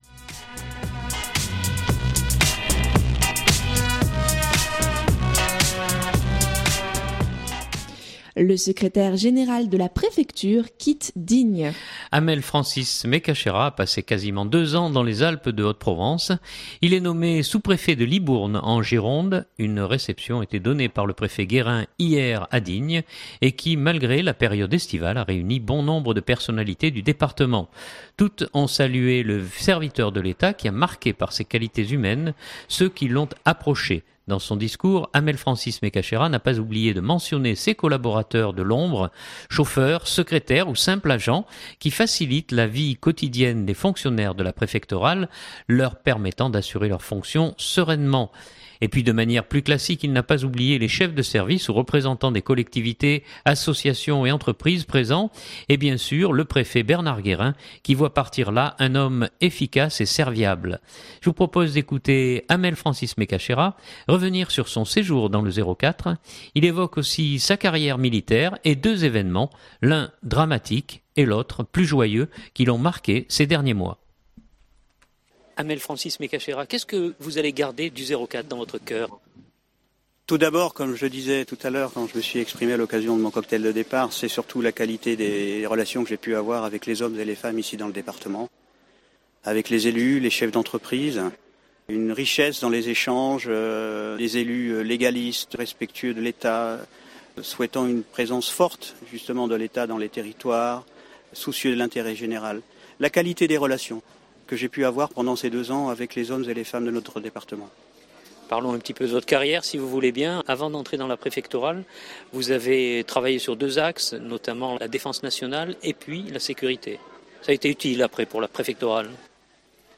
Je vous propose d’écouter Hamel-Francis Mekachera revenir sur son séjour dans le 04, il évoque aussi sa carrière militaire et deux événements l’un dramatique et l’autre plus joyeux qui l’ont marqué ces derniers mois.